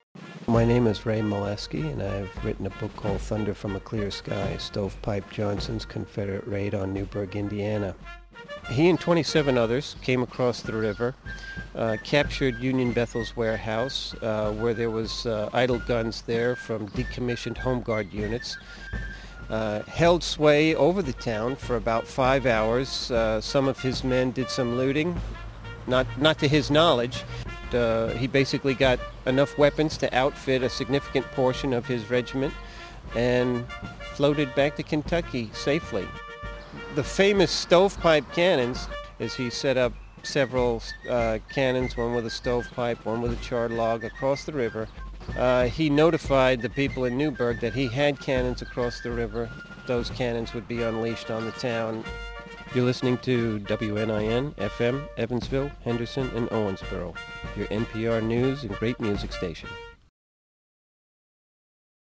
Radio clip
The first clip was done standing outside the Rutledge House in Newburgh (called the Newburgh Clip) and the second clip was done at the upstairs genealogy research section of Willard Library (called the Willard Clip). The voice you hear in both clips is the nasal New York of yours truly.